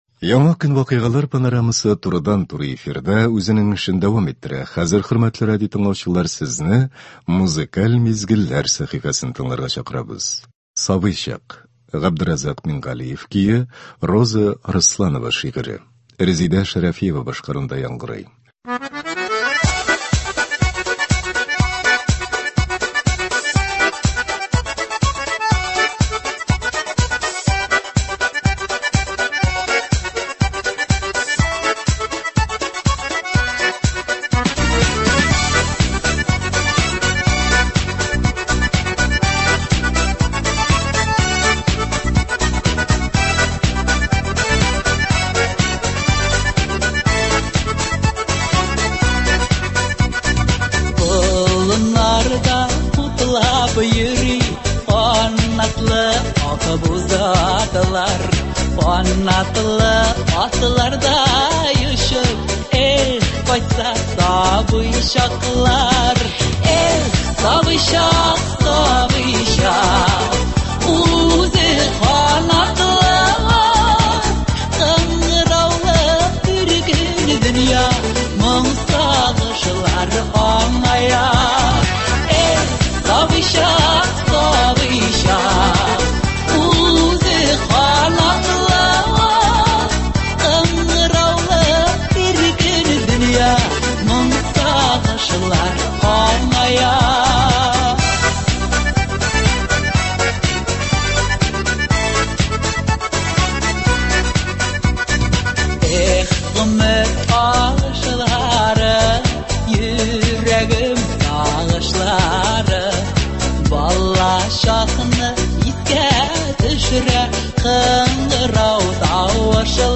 Бүгенге иртәнге концертта яраткан җырларыбыз яңгырый.